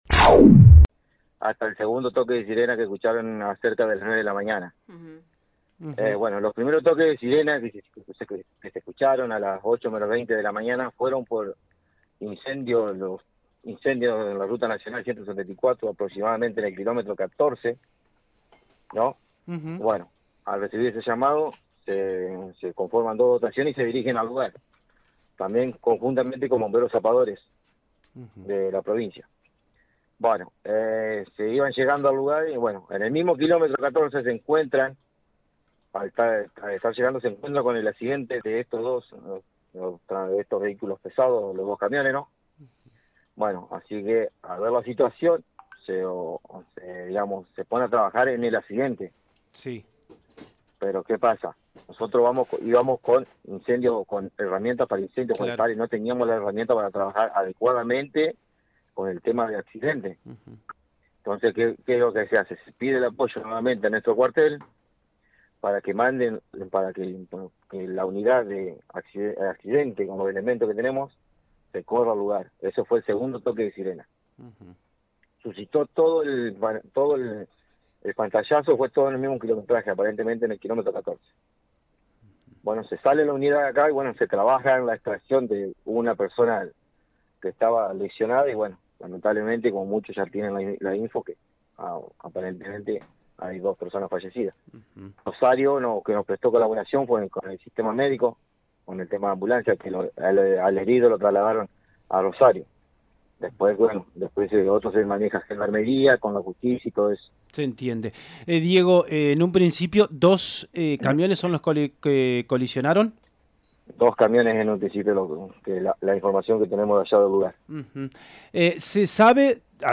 Bombero Voluntario